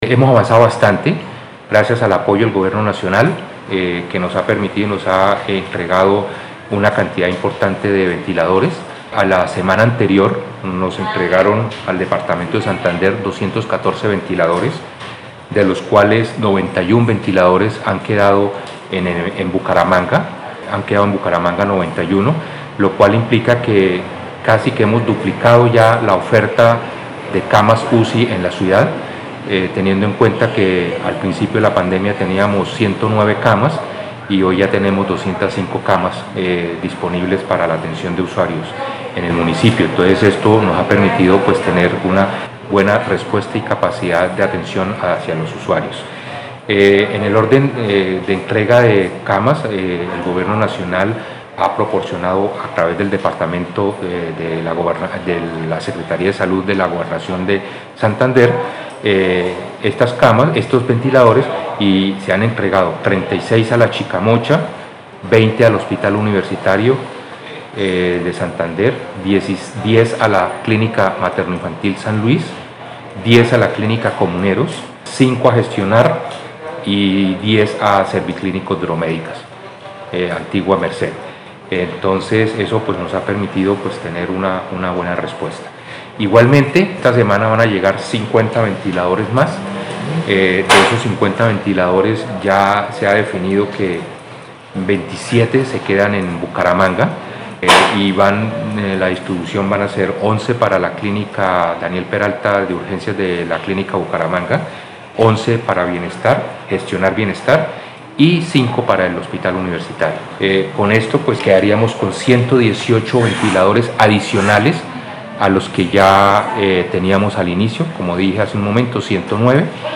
Descargar audio: Nelson Helí Ballesteros, secretario de Salud de Bucaramanga
Nelson-Ballesteros-secretario-de-Salud-de-Bucaramanga.mp3